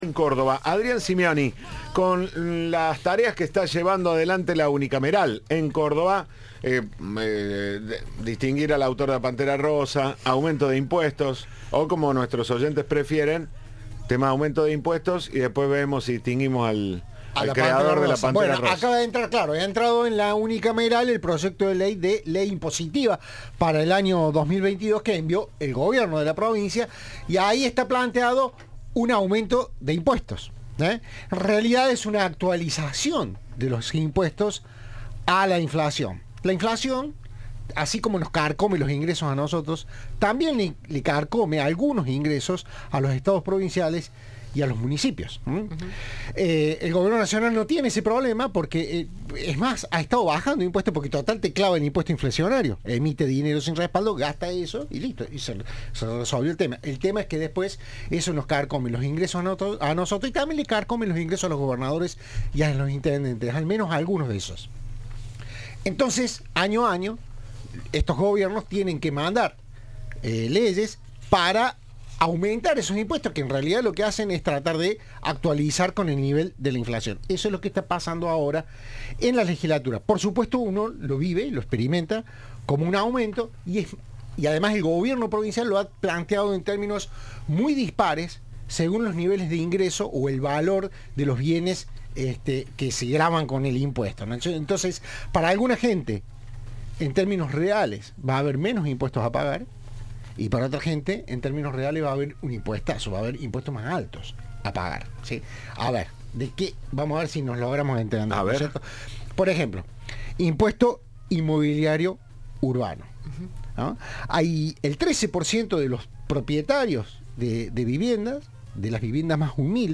En diálogo con Cadena 3, el ministro explicó: "Lo que tratamos de tener en cuenta es la profundización de la desigualdad social que se ve en Argentina, en donde algunos sectores se han visto mucho más castigados por la crisis, sobre todo los trabajadores informales".